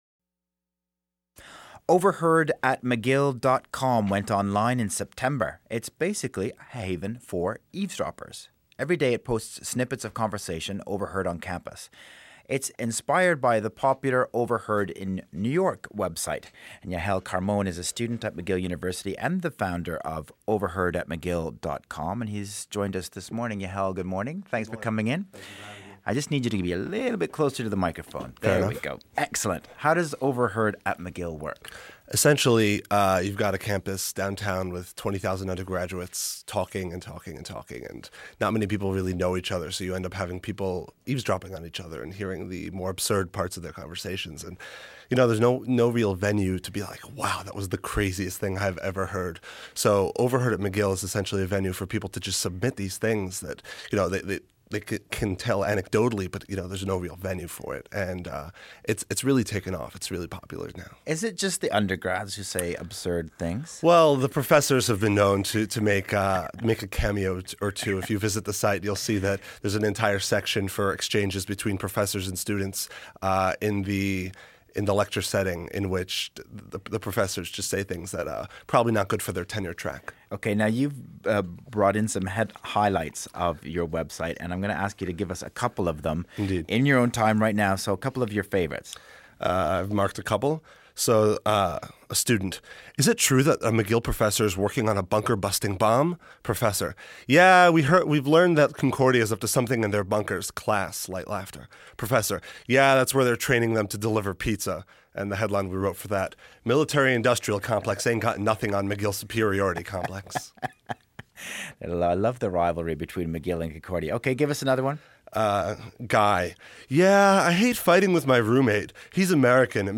CBC Radio One Interview(mp3)